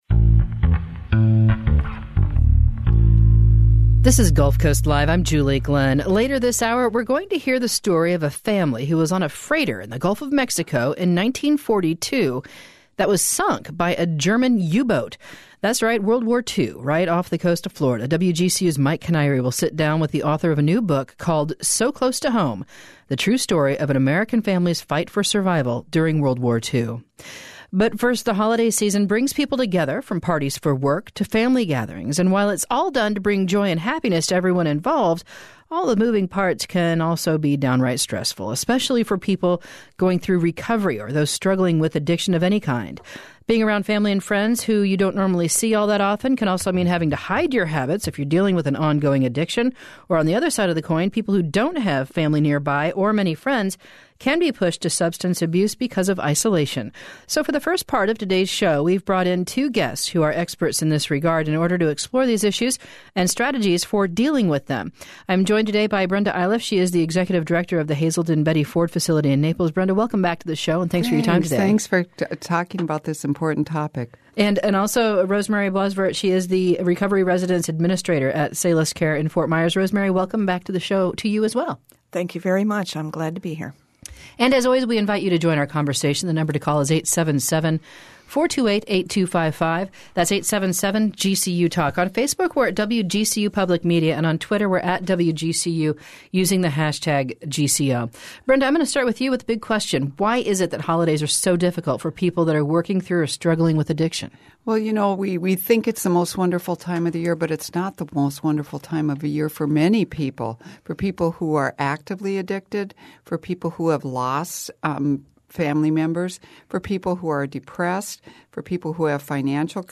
So we're joined by two guests who are experts in this regard to explore these issues, and strategies for dealing with them.